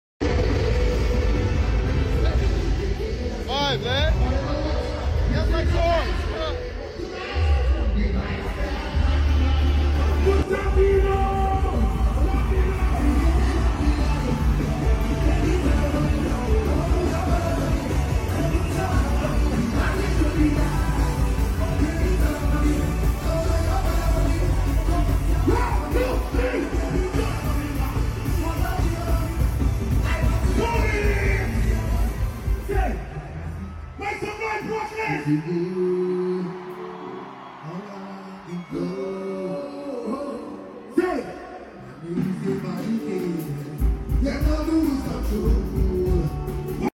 live on stage at Barclays Center